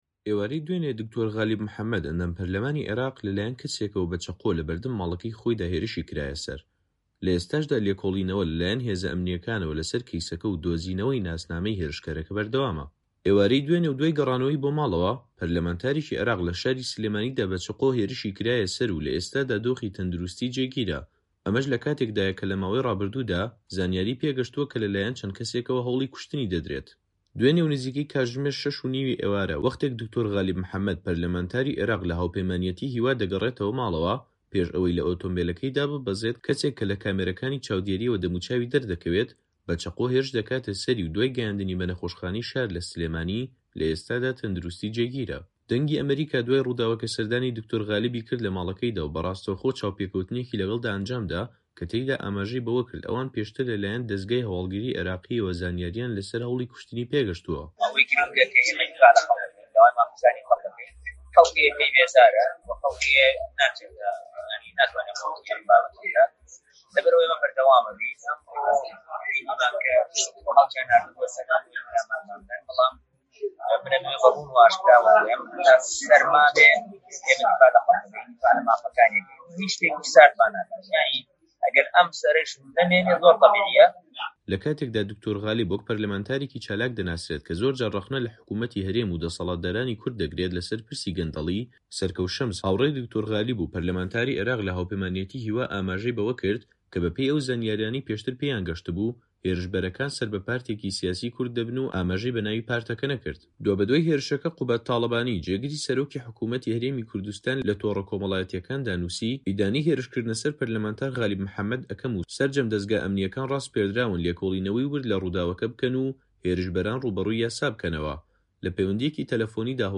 دەنگی ئەمەریکا دوای ڕووداوەکە سەردانی د.غالبی کرد لە ماڵەکەیدا و بە ڕاستەوخۆ چاوپێکەوتنێکی لەگەڵدا ئەنجامدا، کە تێیدا ئاماژەی بەوە کرد ئەوان پێشتر لەلایەن دەزگای هەواڵگری عێراقییەوە زانیارییان لەسەر هەوڵی کوشتنی پێگەشتووە.